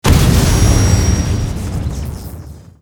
academic_newskill_gravitygrenade_02_charge.ogg